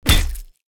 metal_punch_finisher_07.wav